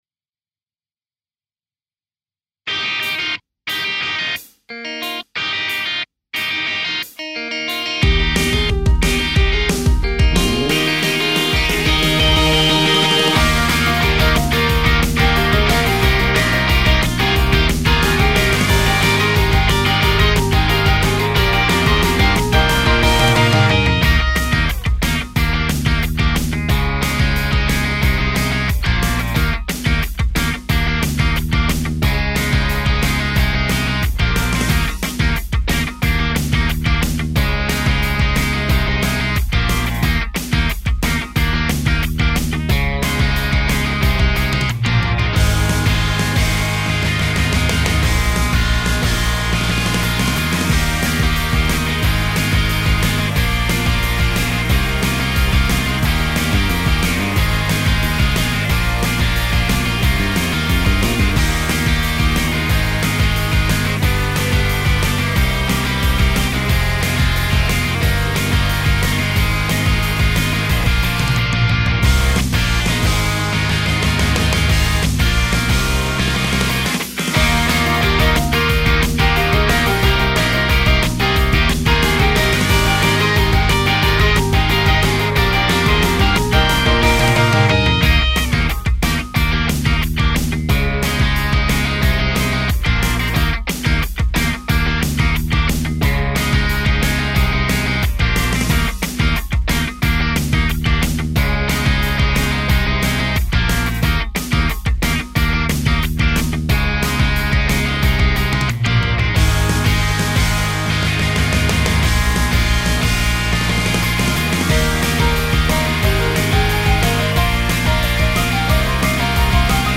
伴奏のみ